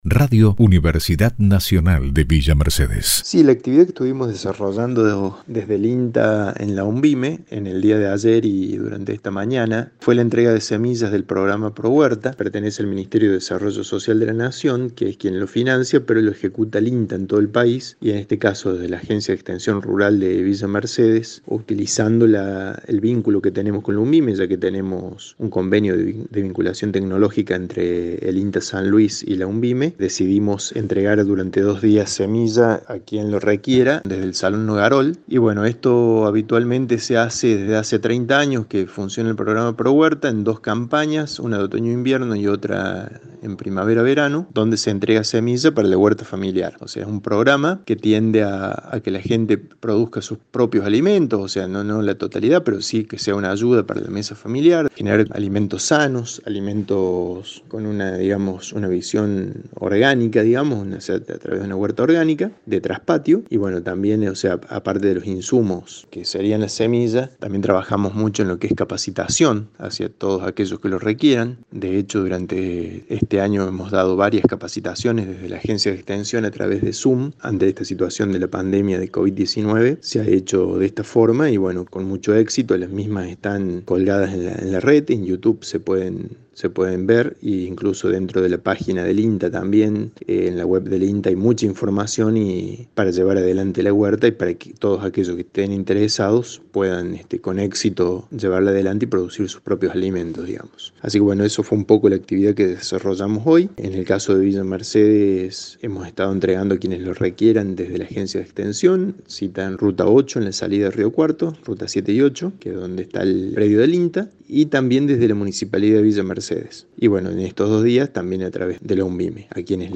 Prensa institucional dialogó con uno de los técnicos del INTA